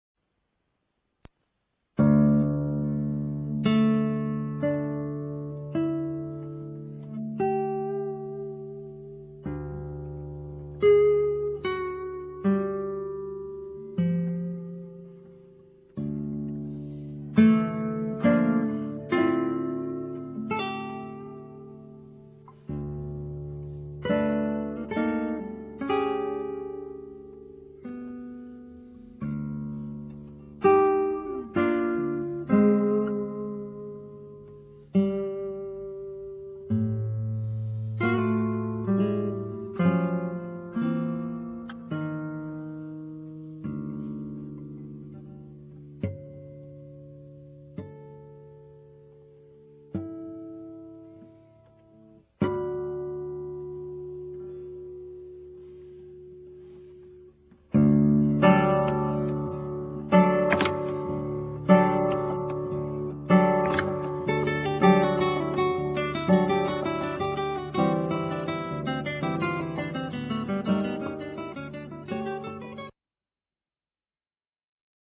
DÚO DE GUITARRAS
dúo de guitarras